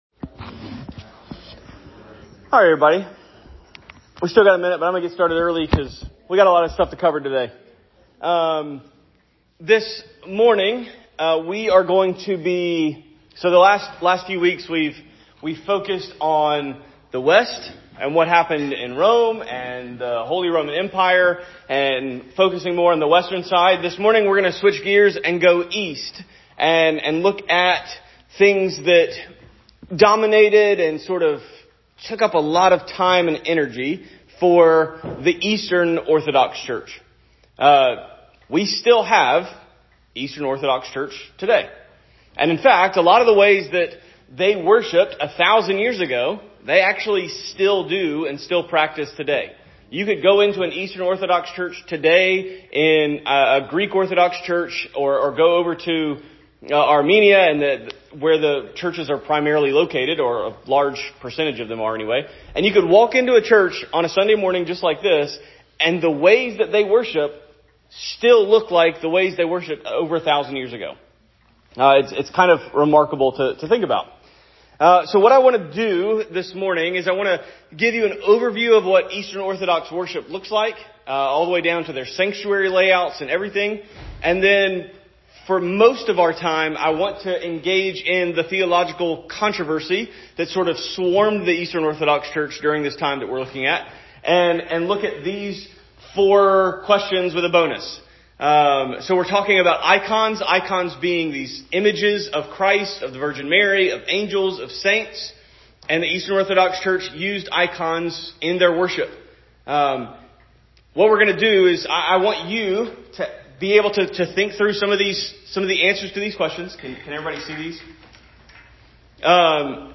Series: Sunday School Lesson